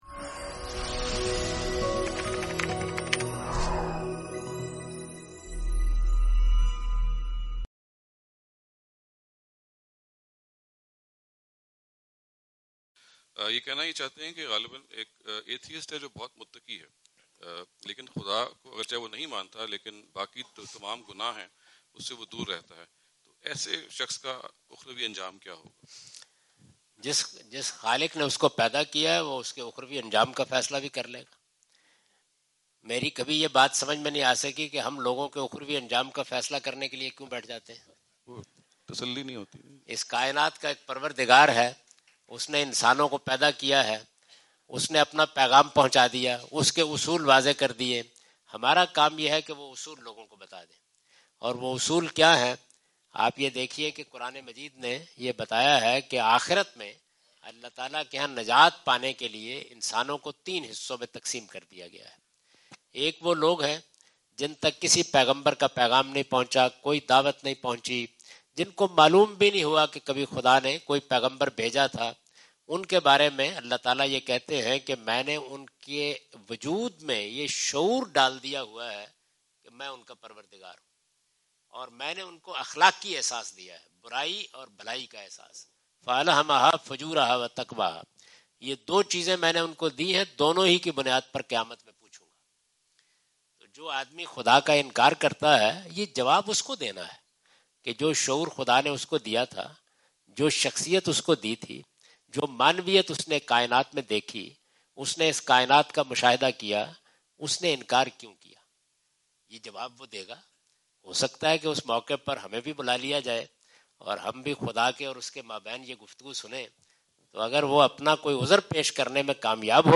Javed Ahmad Ghamidi answer the question about "The Fate of Pious Atheists" during his US visit on June 13, 2015.
جاوید احمد غامدی اپنے دورہ امریکہ 2015 کے دوران سانتا کلارا، کیلیفورنیا میں "نیک سیرت ملحدین کا اخروی انجام" سے متعلق ایک سوال کا جواب دے رہے ہیں۔